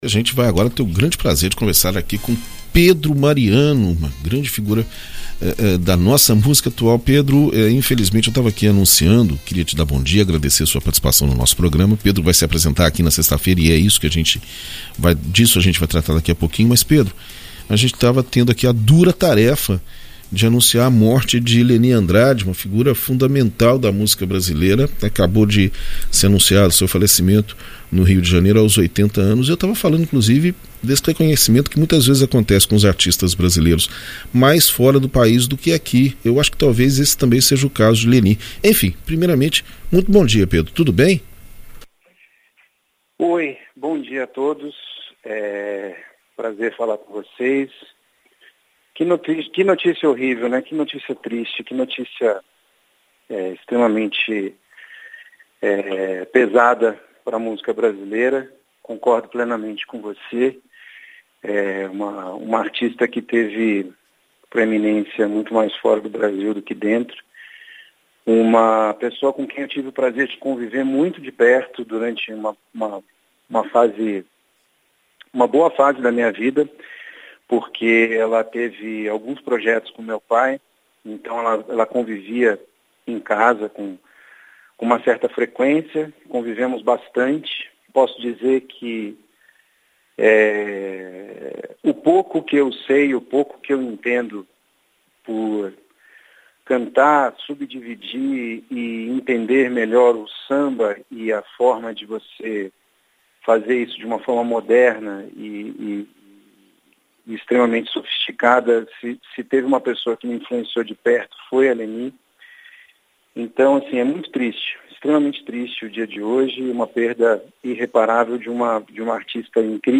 Na entrevista concedida à BandNews FM Espírito Santo nesta segunda-feira (24), o cantor e compositor Pedro Mariano convidou o público para se unir a um grandioso coral no Teatro da Ufes. O espetáculo, que contará com a participação de mais de 40 músicos no palco, promete oferecer uma experiência multisensorial, incluindo apresentações de balé, recitação de poesia, além de muita emoção e música.